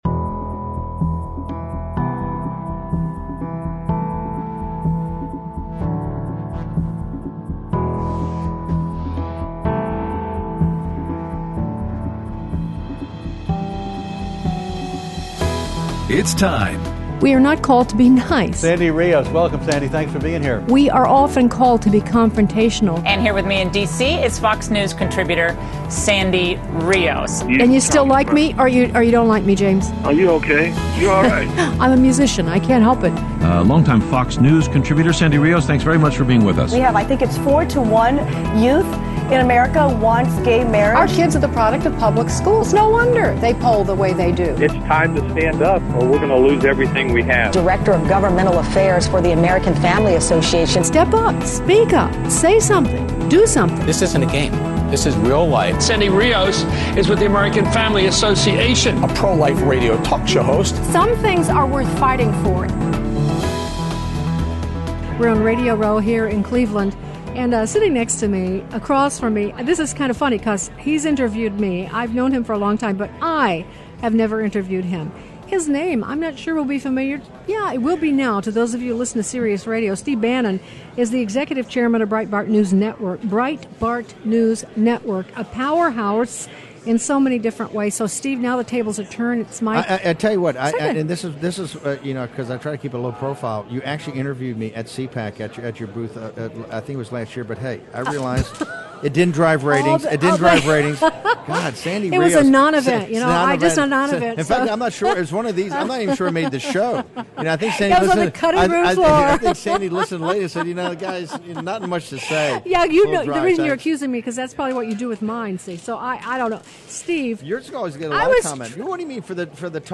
RNC interviews with: Steve Bannon, Lou Dobbs